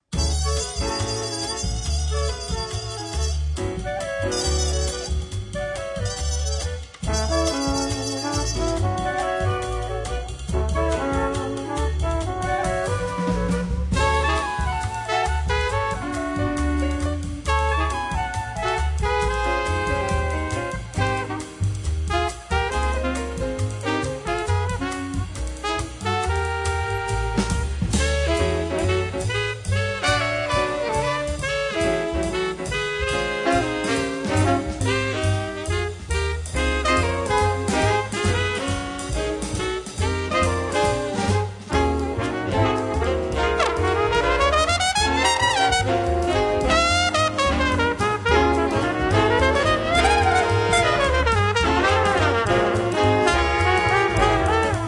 The Best In British Jazz
Recorded Curtis Schwartz Studios January 2003